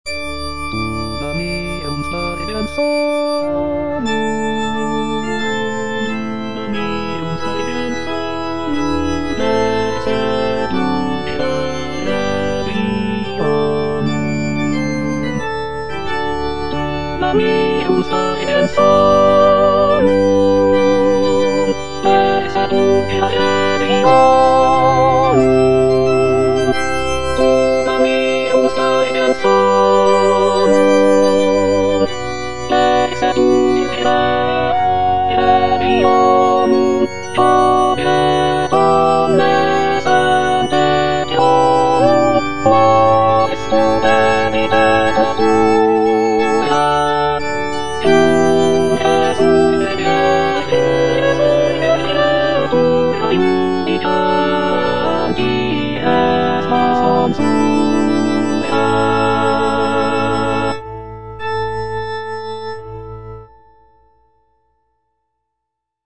Alto (Emphasised voice and other voices) Ads stop